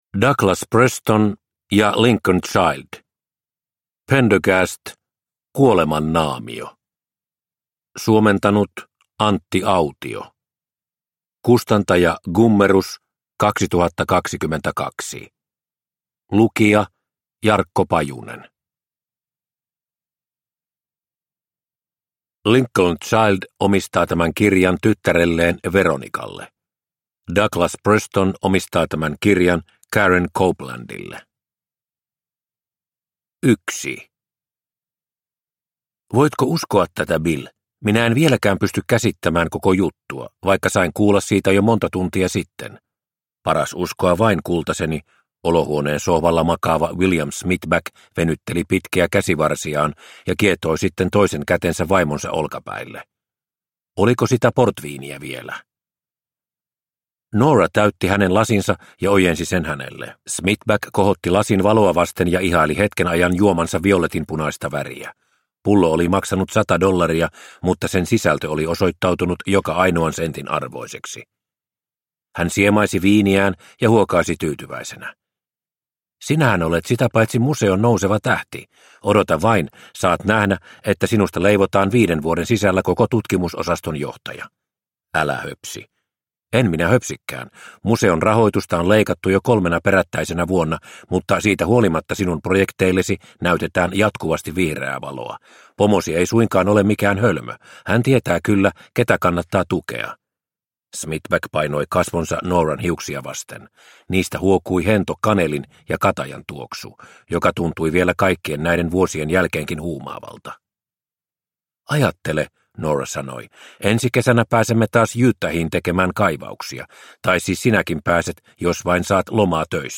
Kuoleman naamio – Ljudbok – Laddas ner